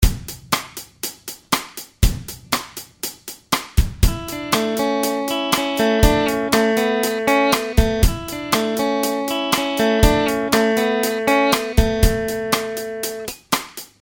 Arpeggio Riff 2 | Download